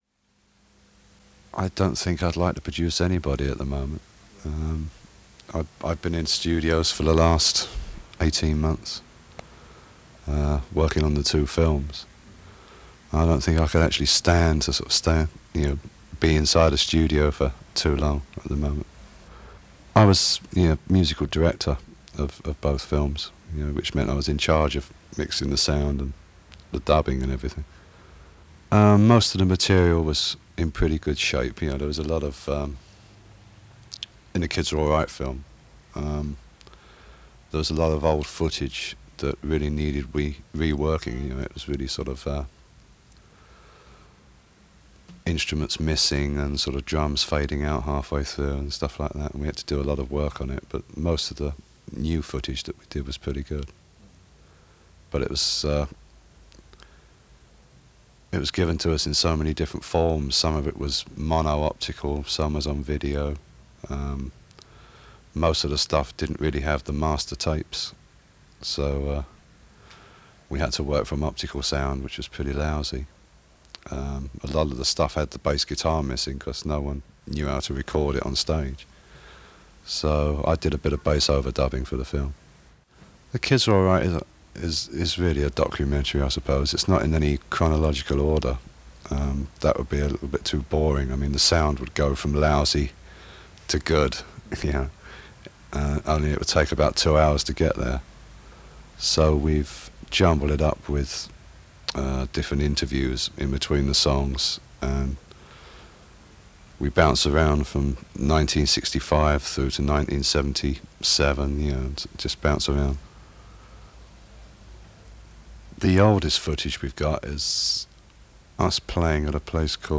The Who’s John Entwistle in an open-ended interview, recorded April 16, 1979.
So, in this open-ended interview (where the interviewer’s questions are missing), John Entwistle has a lot to say and a lot of insights into The Who and what made them tick.
He then talks about studio burnout; wanting to be on the road rather than recording new songs all day. The interview is very matter-of-fact with no apologies.
John-Entwistle-interview-1979.mp3